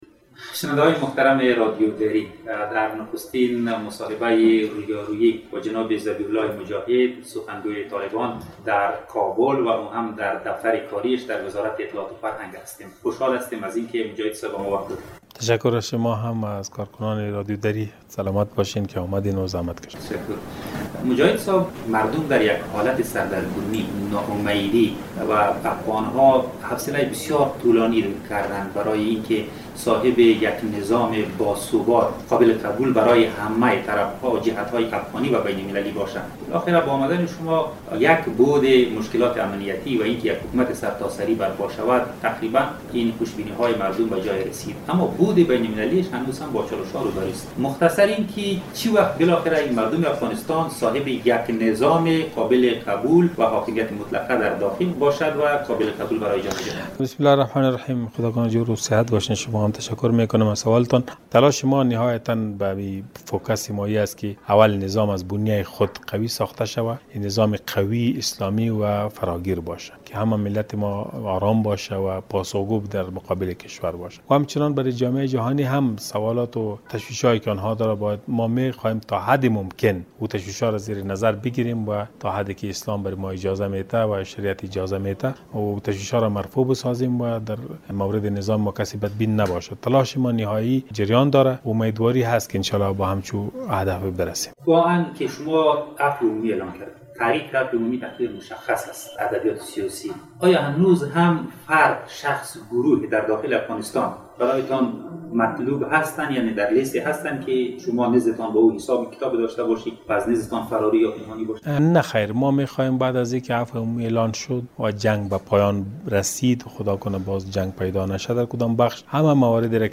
معاون وزارت اطلاعات و فرهنگ و سخنگوی حکومت موقت طالبان در گفت و گوی اختصاصی با رادیو دری تأکید کرد طالبان در حکومت خود برای برقراری نظام اسلامی و فراگیر تل...